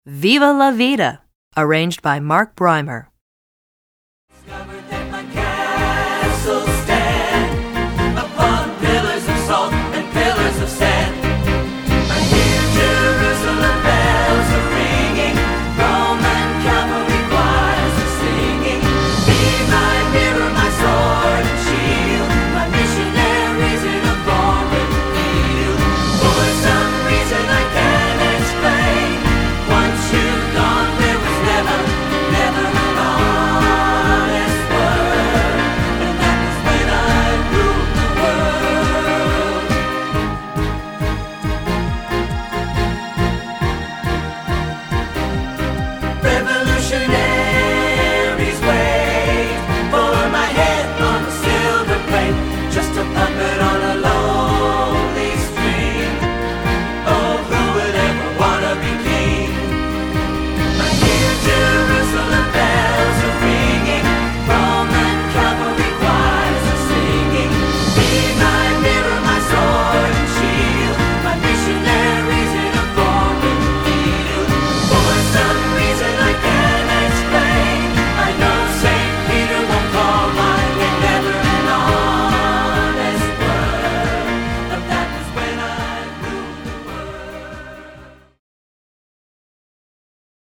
Choral Early 2000's Pop
SAB